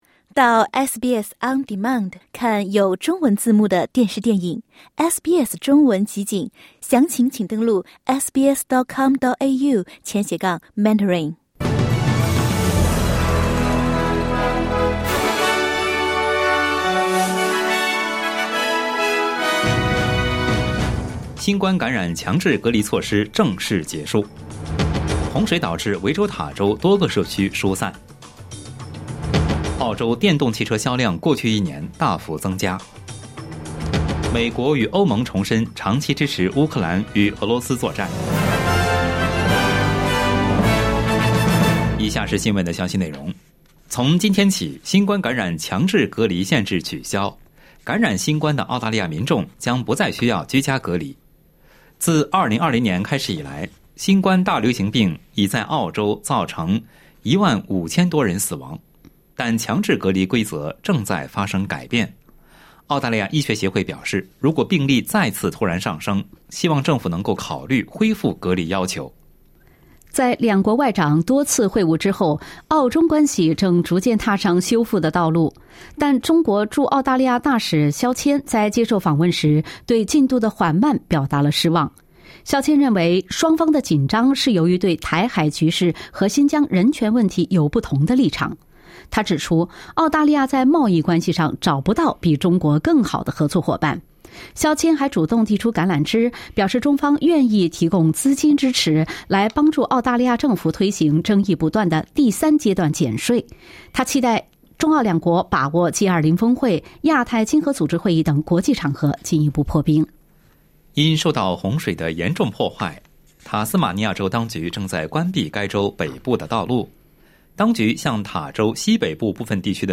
SBS早新闻（10月14日）